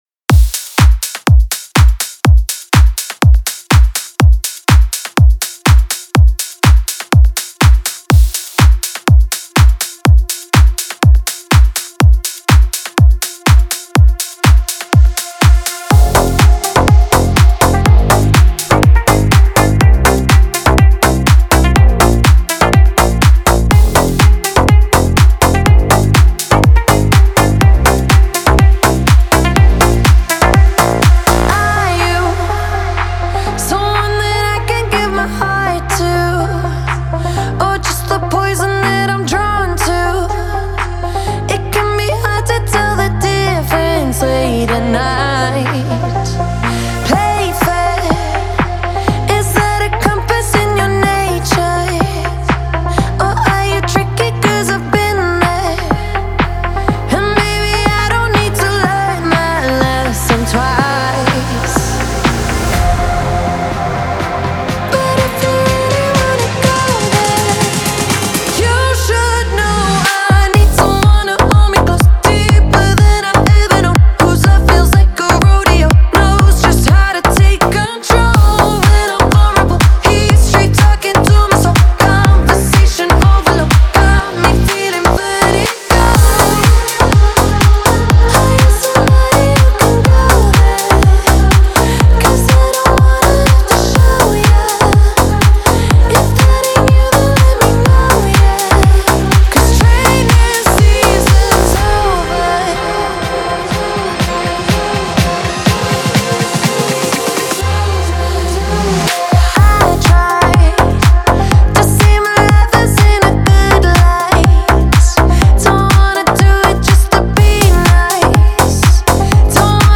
• Жанр: Electronic, Pop, Dance